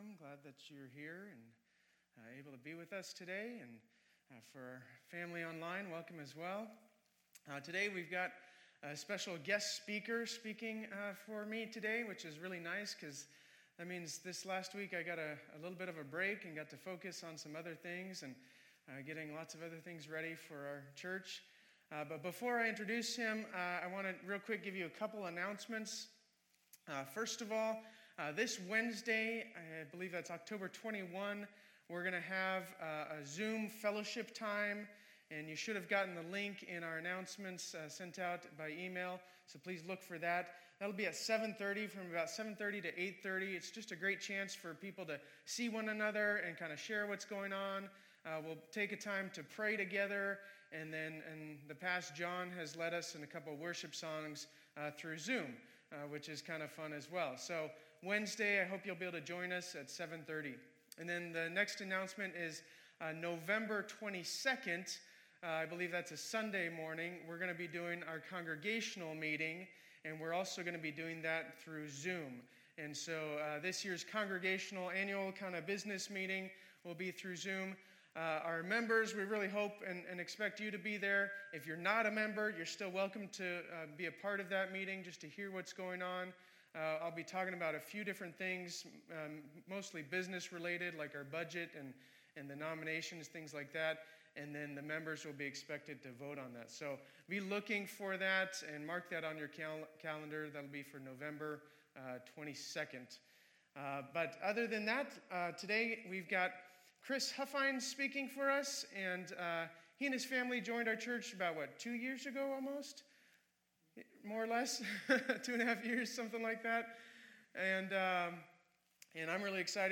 2020-10-18 Sunday Service Guest Speaker | Faith Community Church